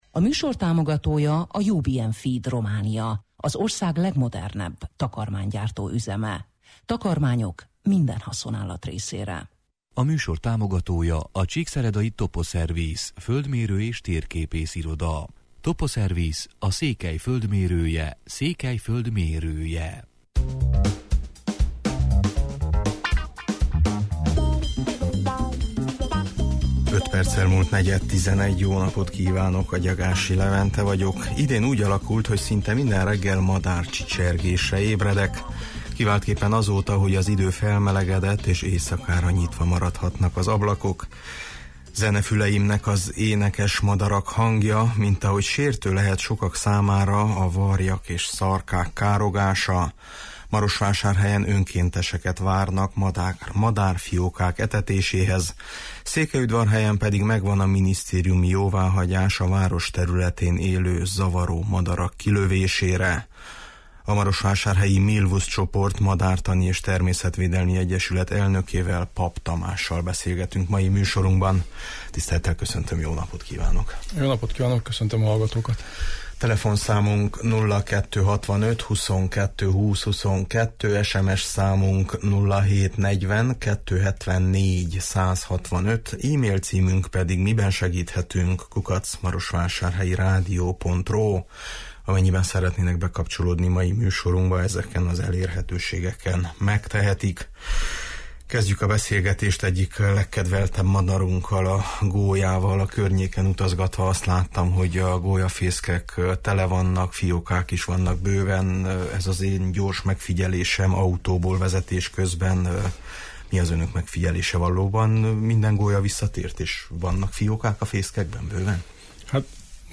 Nyár elején gyakran megesik, de a nyár folyamán is találkozhatunk azzal a jelenséggel, hogy a fészekből eltávozó, repülni még nem tudó madárfiókákat látunk a járdán vagy az úttesten. Mi a teendőnk ilyenkor? – ezt is megtudjuk a következő beszélgetésből.